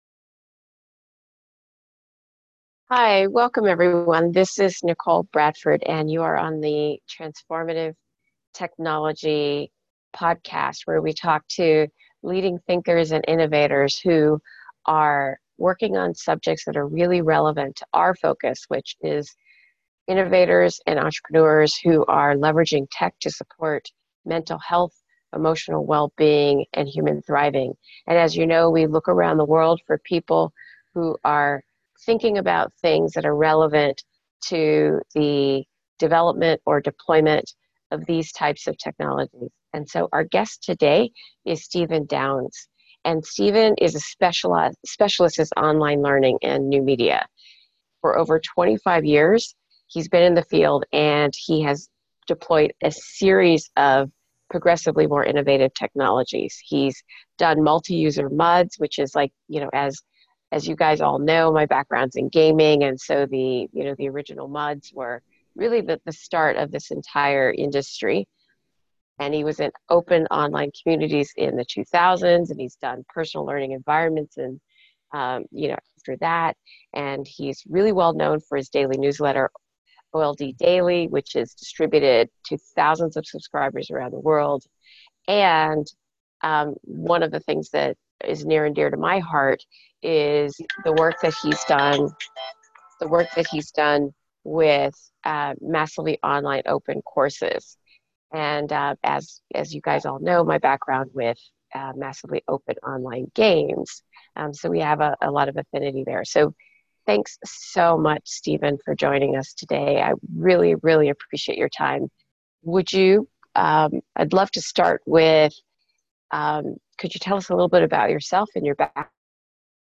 The Future of Well-Being in a Tech-Saturated World Previous Next Page: / Author: Downloads: (Old style) [ Audio ] [] Transformative Technology Podcast, Online, via Zoom, Interview, Apr 11, 2019.